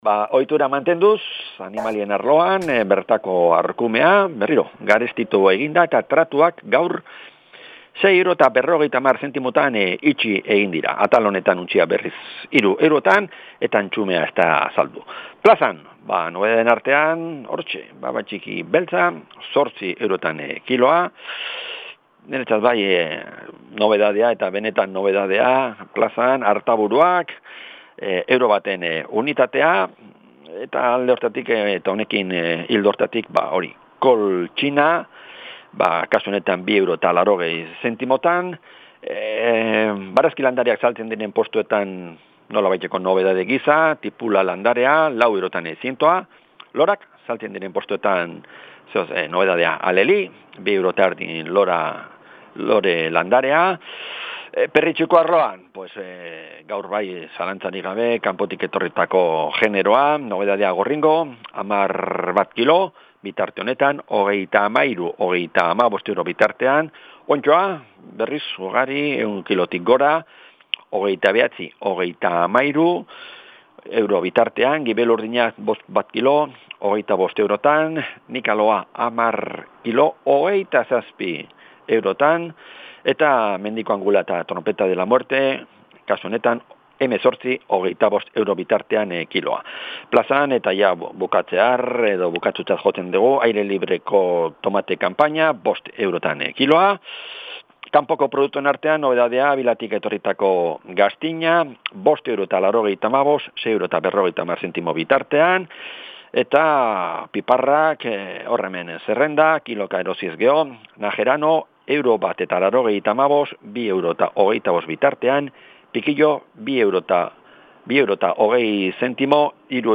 Alkarrizketa Ordizia 25-10-08 Reproducir episodio Pausar episodio Mute/Unmute Episode Rebobinar 10 segundos 1x Fast Forward 30 seconds 00:00 / 2:54 Suscribir Compartir Feed RSS Compartir Enlace Incrustar